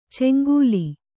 ♪ ceŋgūli